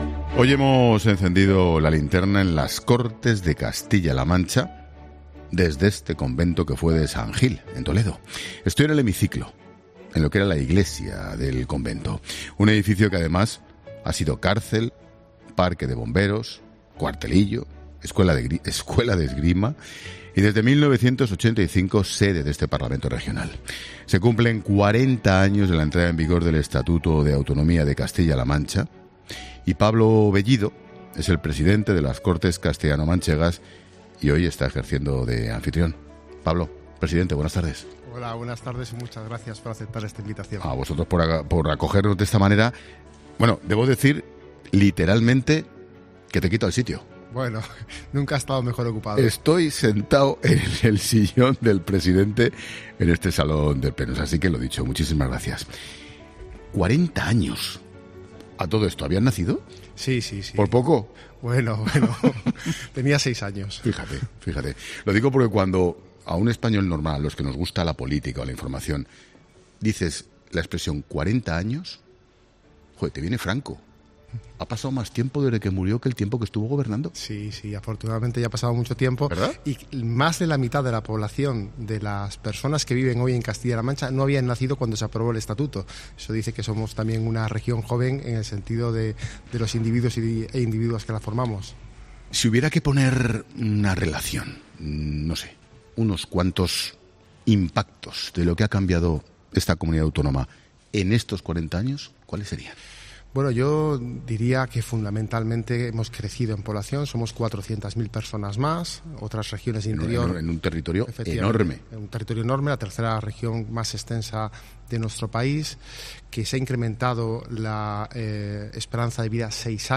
El presidente de las Cortes de Castilla-La Mancha charla con Expósito en La Linterna por los 40 años de la entrada en vigor del Estatuto
Lleva siendo sede de las Cortes desde 1985, pero hoy le ha cedido un hueco al programa La Linterna.